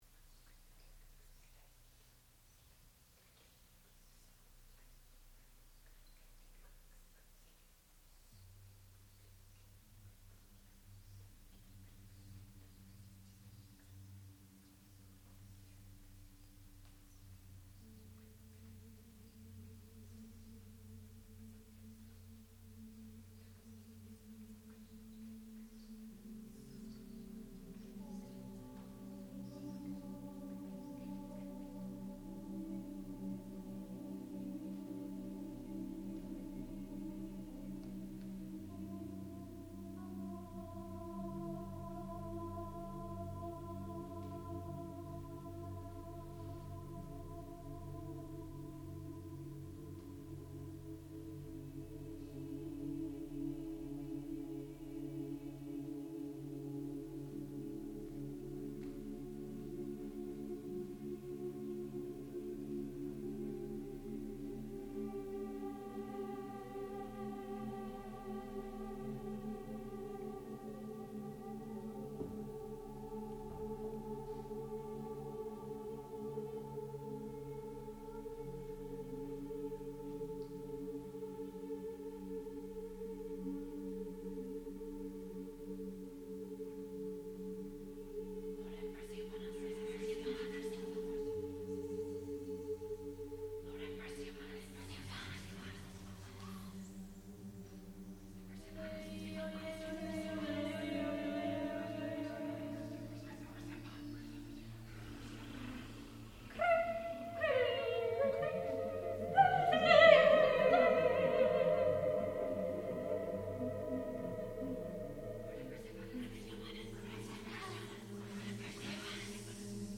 sound recording-musical
classical music
Master's Recital